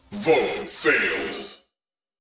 🌲 / midnight_guns mguns mgpak0.pk3dir sound announcer
vote_failed_00.wav